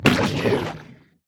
Minecraft Version Minecraft Version latest Latest Release | Latest Snapshot latest / assets / minecraft / sounds / entity / squid / death2.ogg Compare With Compare With Latest Release | Latest Snapshot